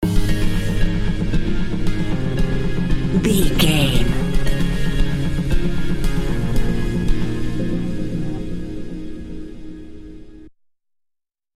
Dance Stinger.
Aeolian/Minor
ethereal
dreamy
cheerful/happy
groovy
synthesiser
drum machine
house
electro dance
techno
trance
instrumentals
synth leads
synth bass
upbeat